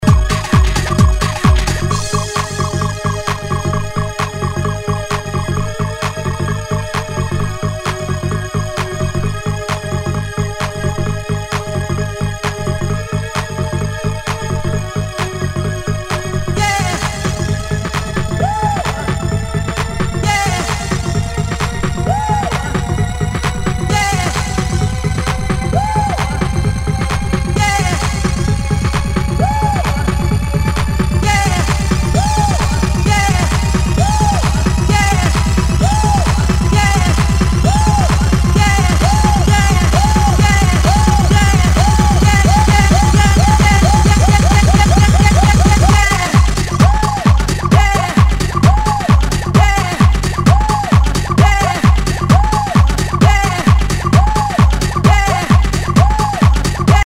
HOUSE/TECHNO/ELECTRO
ナイス！ファンキー・ハウス！